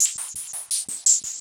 RI_RhythNoise_170-01.wav